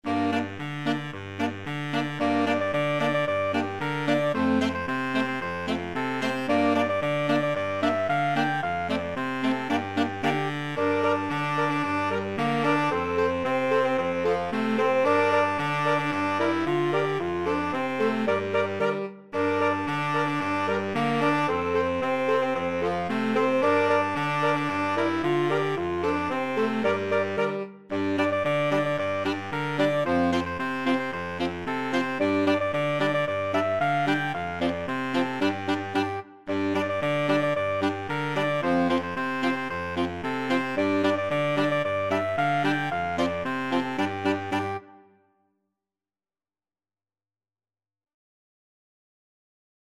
Allegro moderato = c. 112 (View more music marked Allegro)
2/4 (View more 2/4 Music)
Saxophone Quartet  (View more Easy Saxophone Quartet Music)